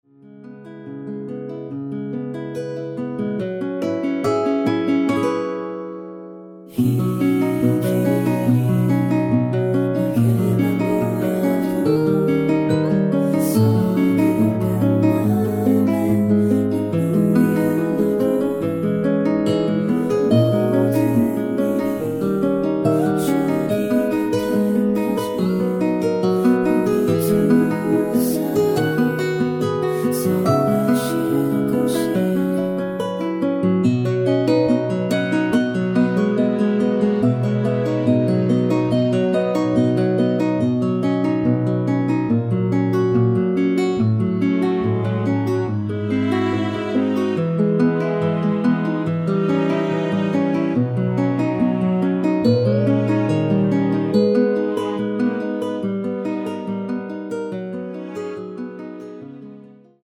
원키에서 (+2)올린 코러스 포함된MR 입니다.
원곡의 보컬 목소리를 MR에 약하게 넣어서 제작한 MR이며